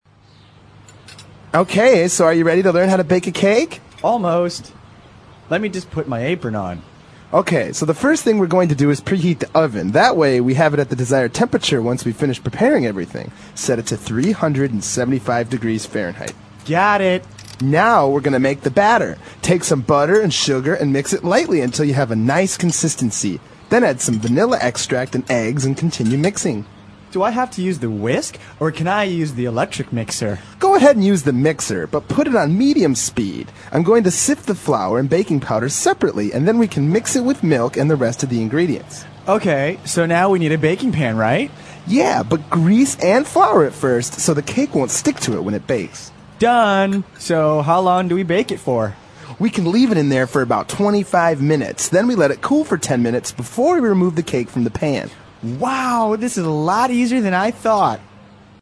纯正地道美语(外教讲解)325：烘烤蛋糕的步骤 听力文件下载—在线英语听力室